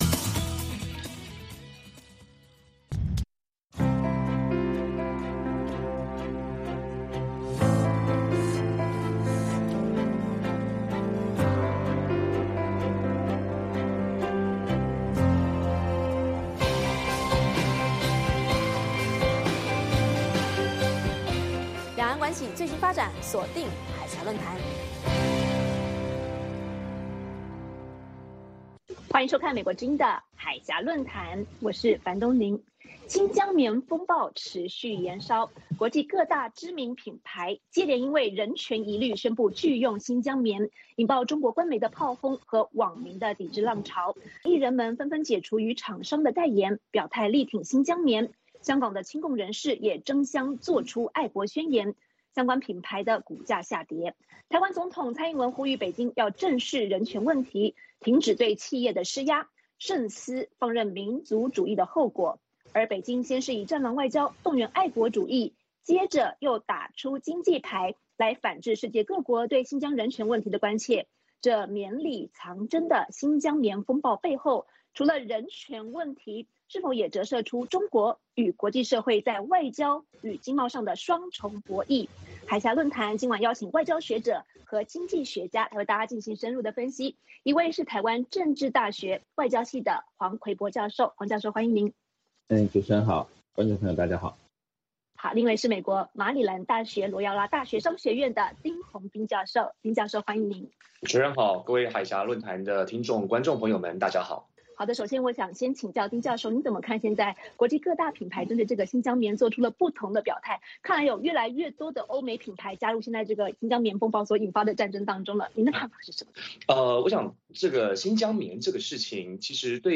美国之音中文广播于北京时间每周日晚上9点播出《海峡论谈》节目(电视、广播同步播出)。《海峡论谈》节目邀请华盛顿和台北专家学者现场讨论政治、经济等各种两岸最新热门话题。